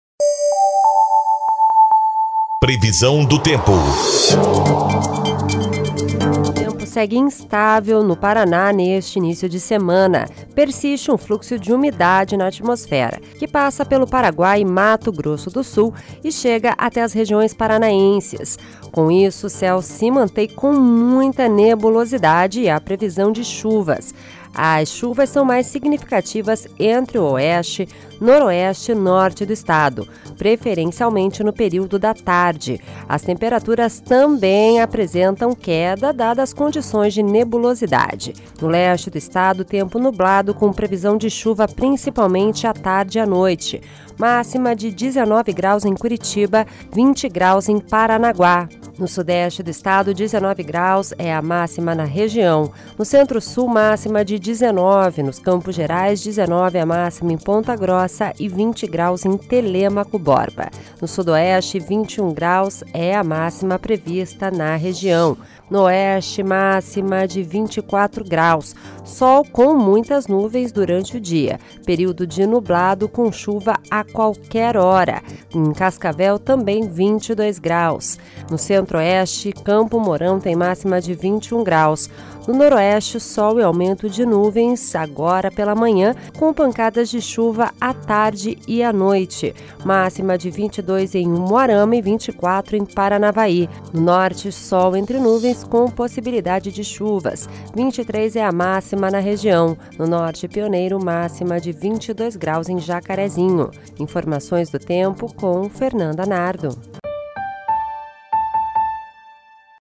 Previsão do Tempo (08/08)